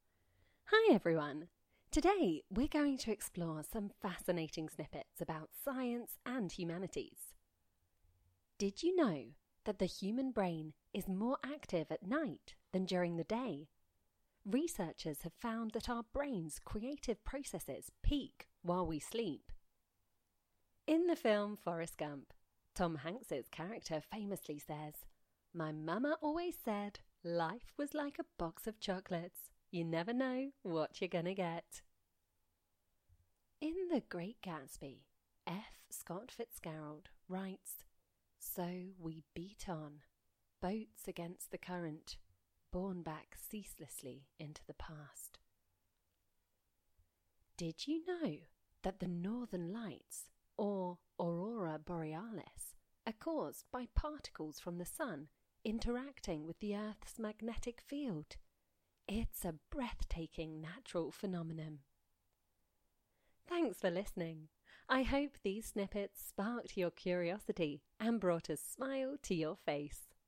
Where voices are not provided, as is typically the case for open source models, we use voices clips from professional voice actors as source files for generating speech.